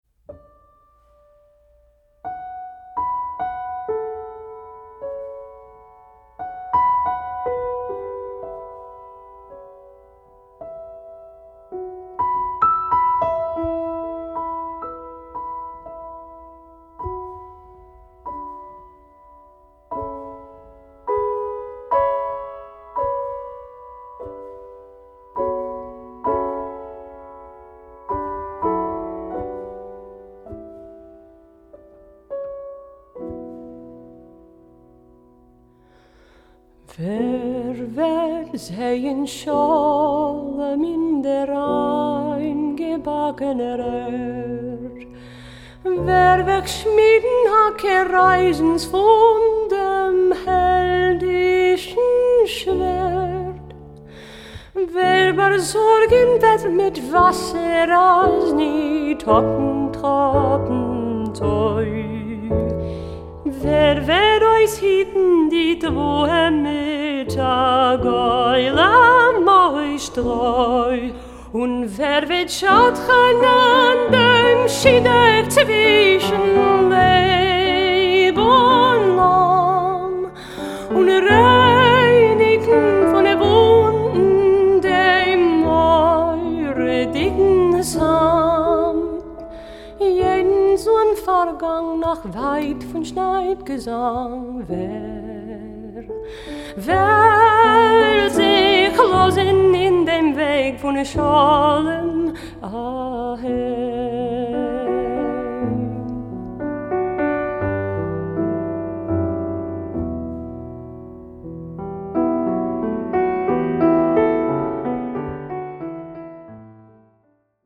Genres: Klezmer, Jazz, World.
clarinet
vocals